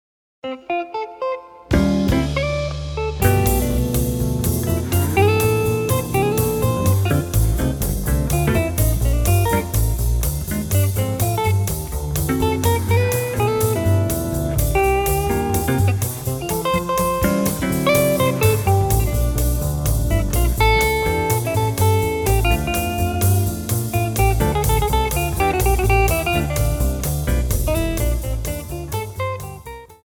acoustic seven-string guitar
electric guitar, vocals
pedal steel guitar
acoustic bass
drums